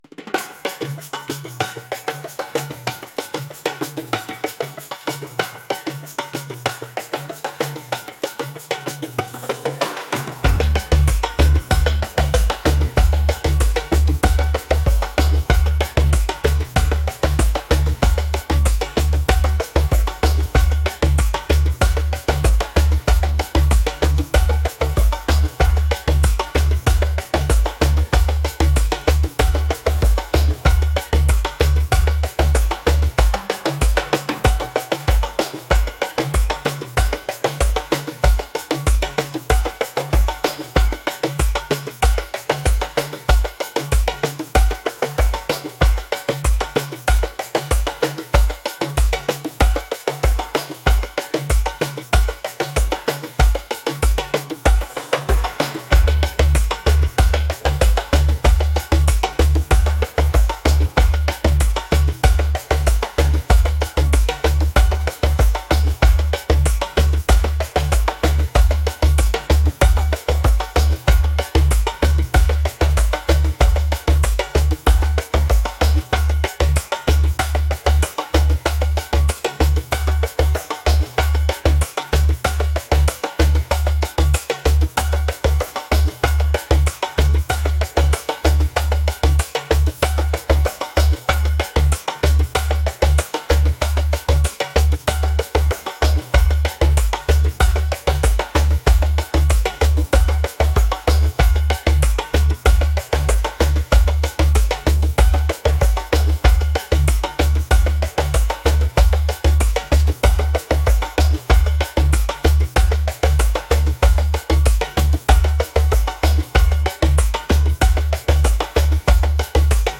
world | upbeat